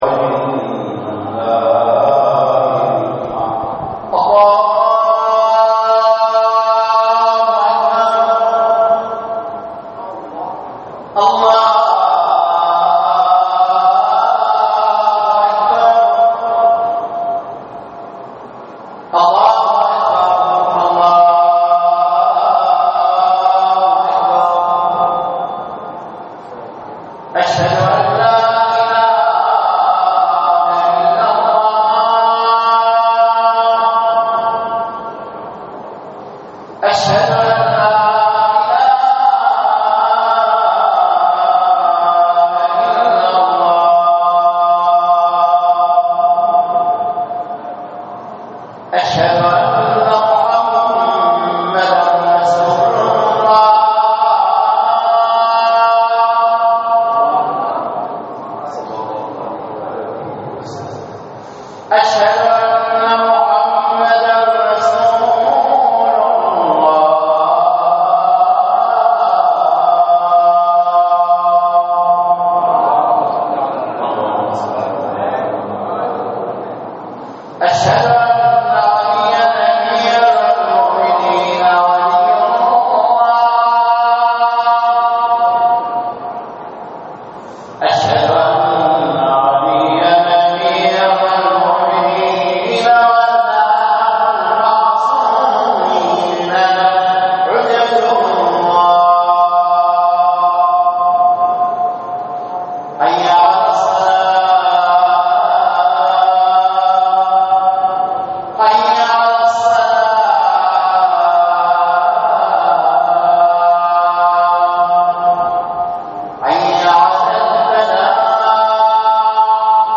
صلاة الجمعة في مدينة الناصرية - تقرير صوتي مصور -
للاستماع الى خطبةالجمعة الرجاء اضغط هنا